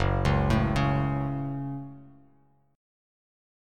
Gbm Chord
Listen to Gbm strummed